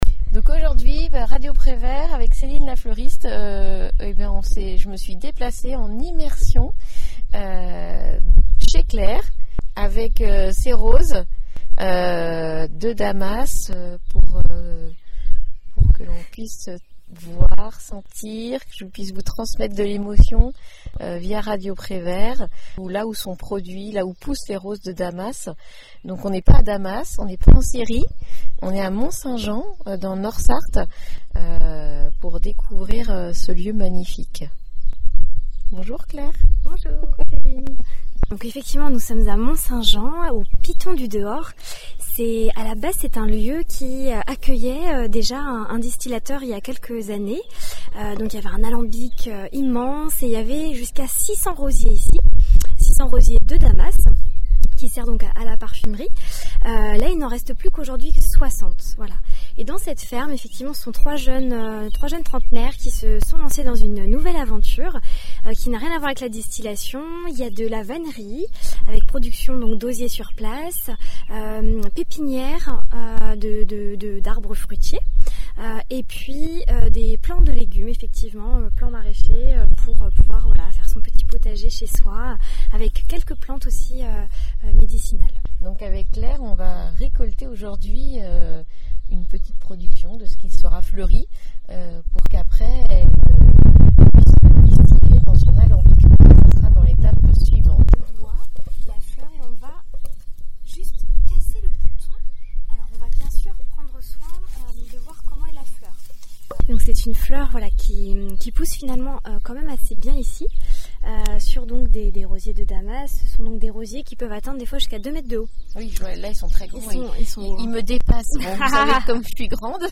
reportage au Piton du Dehors à Mont-Saint-Jean